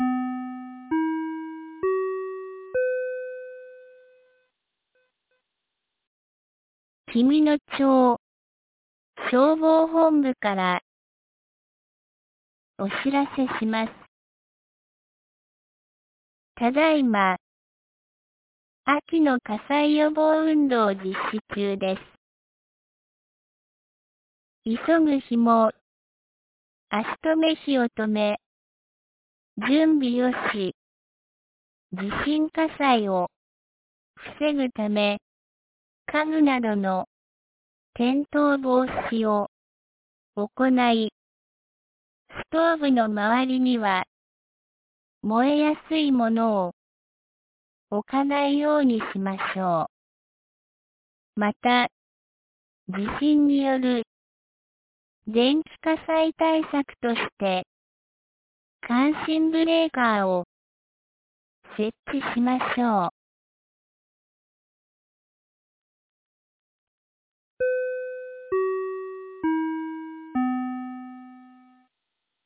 2025年11月12日 17時06分に、紀美野町より全地区へ放送がありました。